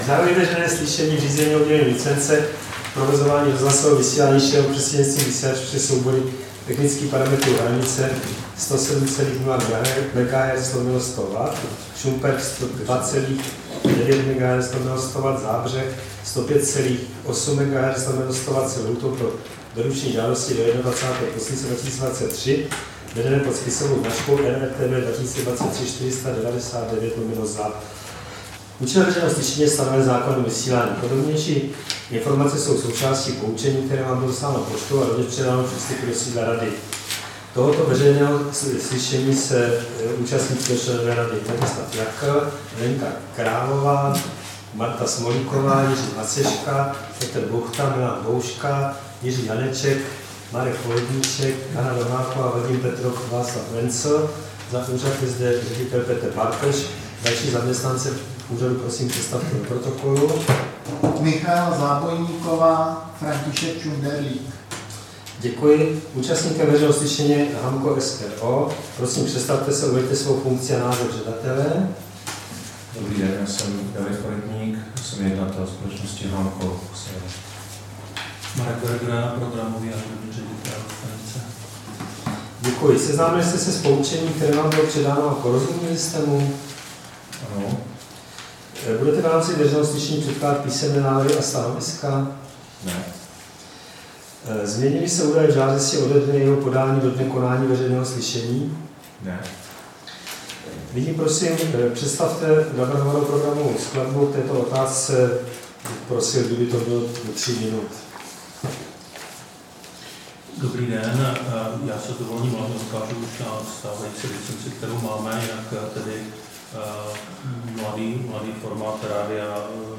Veřejné slyšení v řízení o udělení licence k provozování rozhlasového vysílání šířeného prostřednictvím vysílačů se soubory technických parametrů Hranice 107,0 MHz/100 W, Šumperk 102,9 MHz/100 W, Zábřeh 105,8 MHz/100 W
Místem konání veřejného slyšení je sídlo Rady pro rozhlasové a televizní vysílání, Škrétova 44/6, 120 00 Praha 2.